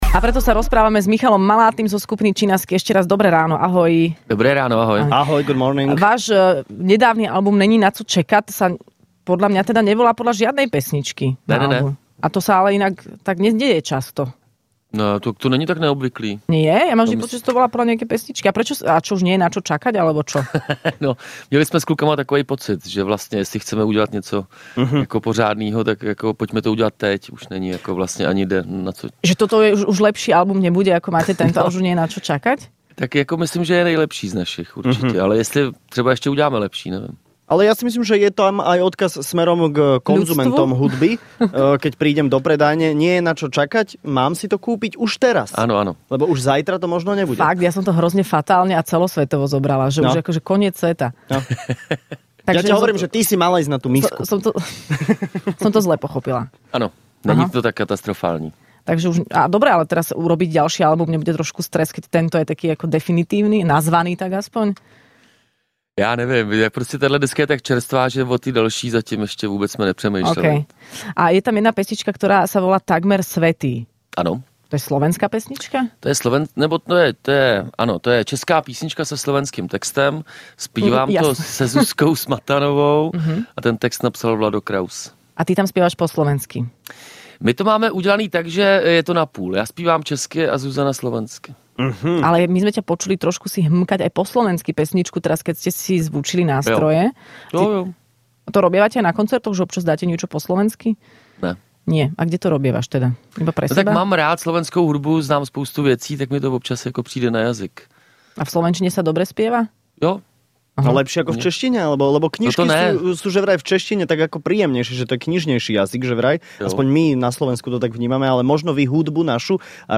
Skupina Chinaski prišla do Rannej šou predstaviť svoj nový album